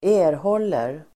Uttal: [²'e:rhål:er el. ²'ä:-]